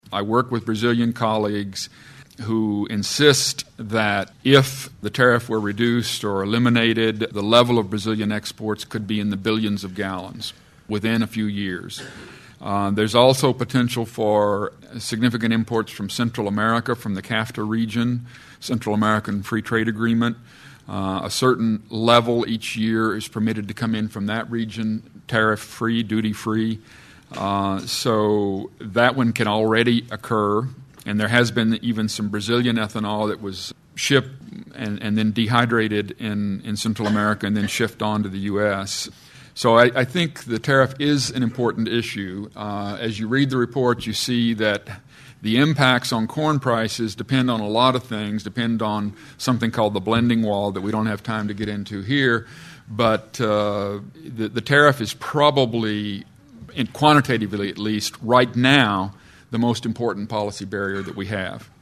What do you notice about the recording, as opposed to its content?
• Seven excerpts from the question and answer session: